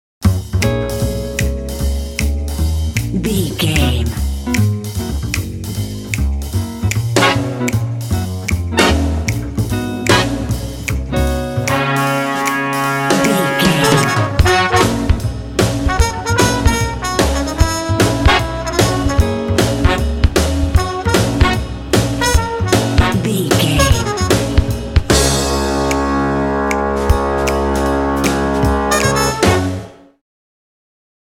Uplifting
Ionian/Major
G♭
electric guitar
trumpet
double bass
drums
saxophone
swing